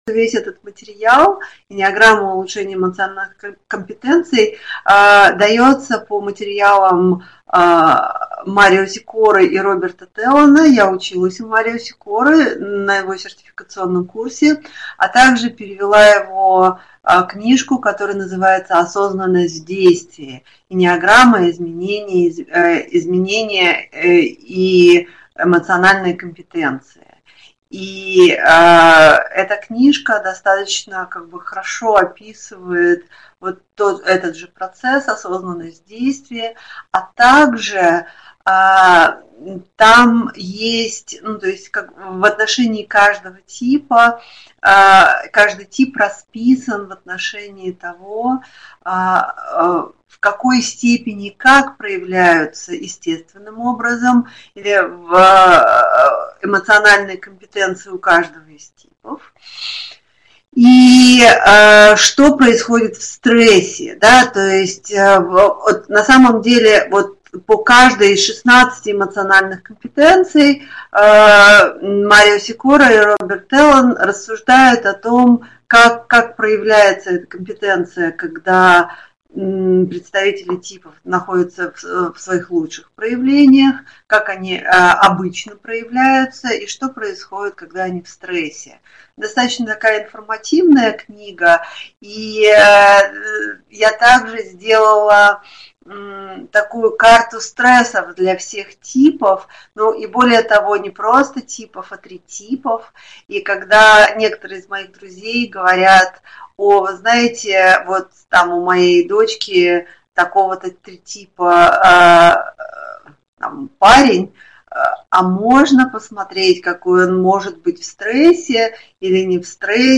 Аудиокнига Осознанность в действии. Тип 4 | Библиотека аудиокниг